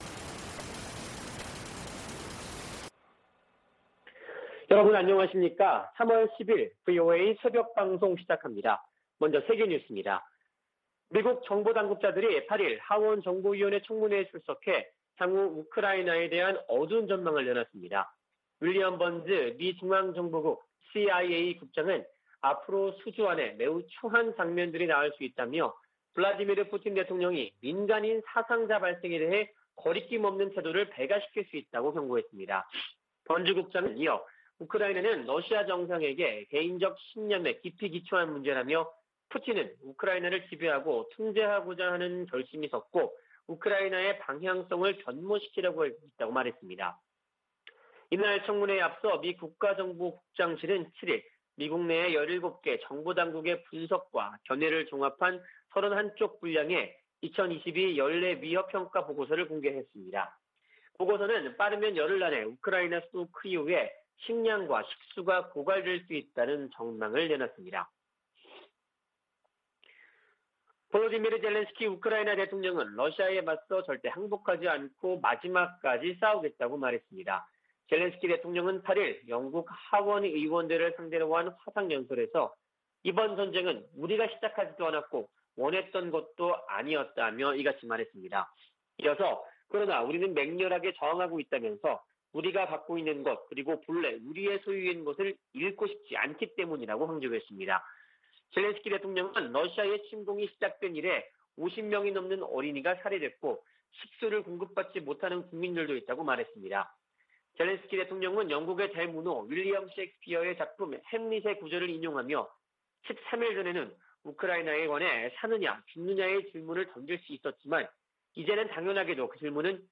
VOA 한국어 '출발 뉴스 쇼', 2022년 3월 10일 방송입니다. 북한이 미국과 동맹국을 겨냥해 핵과 재래식 능력을 지속적으로 확장하고 있다고 미 국가정보국장이 평가했습니다. 북한이 신형 ICBM을 조만간 시험발사할 수 있다는 미군 고위 당국자의 전망이 나왔습니다. 한국 20대 대통령 선거가 9일 실시됐습니다.